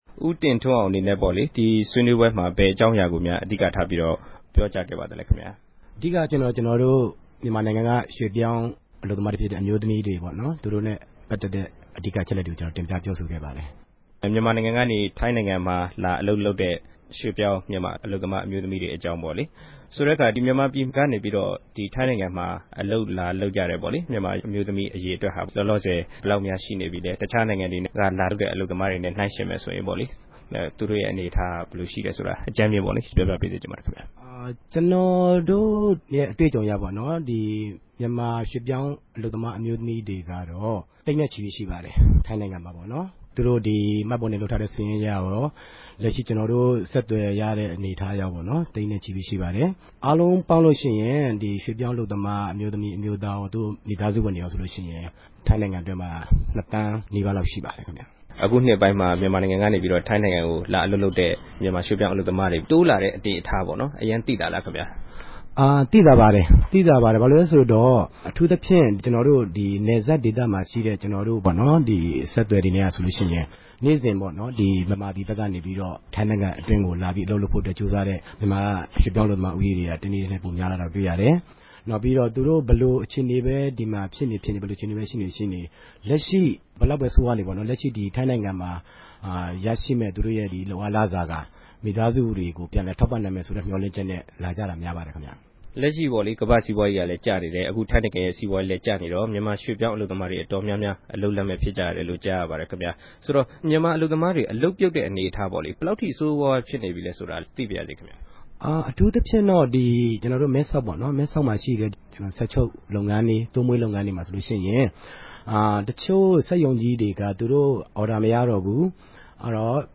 တြေႚဆုံမေးူမန်းခဵက်။